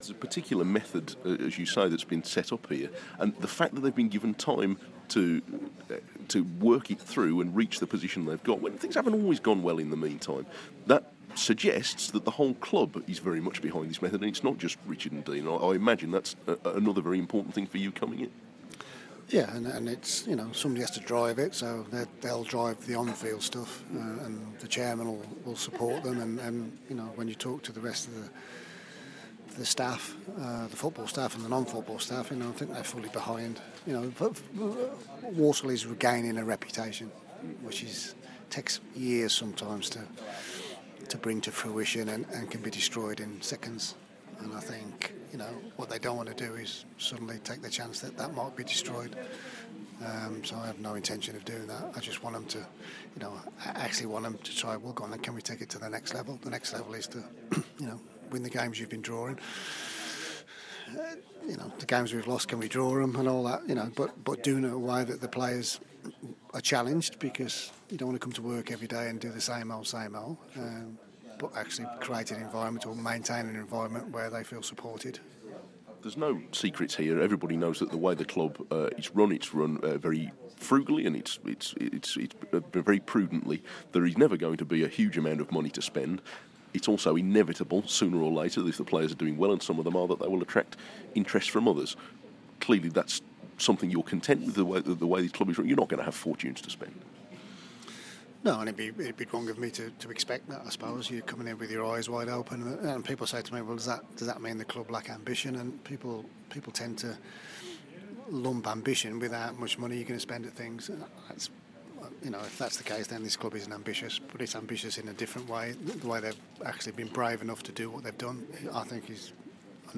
interview part 2